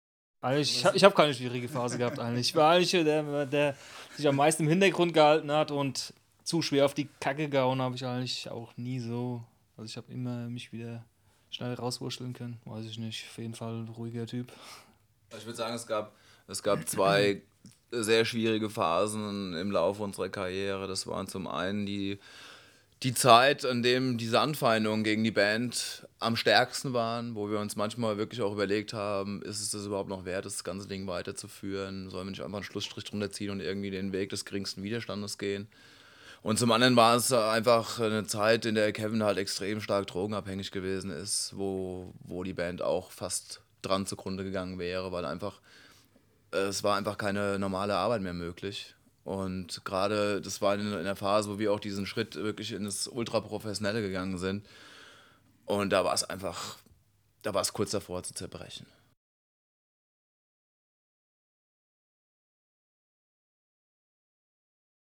Anbei hört hier ein paar Worte der Band zum Release des Albums, direkt aus dem Presse-Kit zur Veröffentlichung.